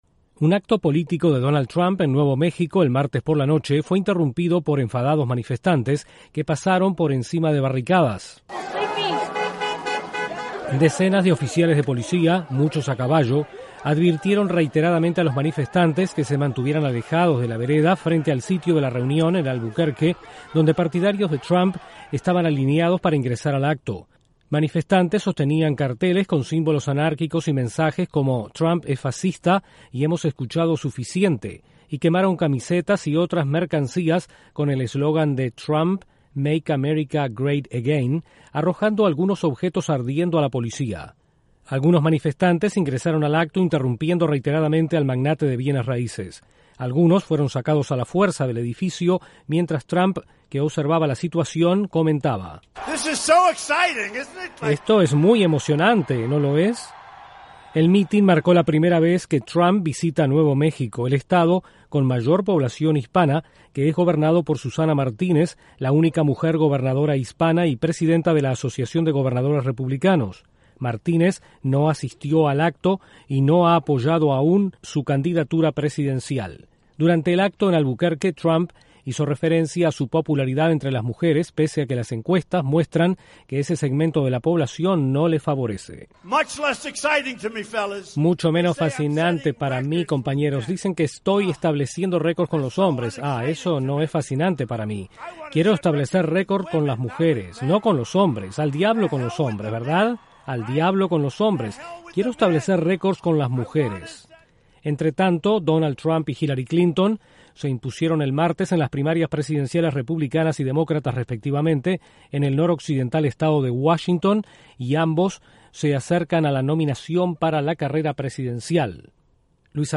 Estallan violentas protestas en acto político de Donald Trump en Nuevo México. Desde la Voz de América en Washington informa